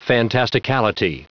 Prononciation du mot fantasticality en anglais (fichier audio)
Prononciation du mot : fantasticality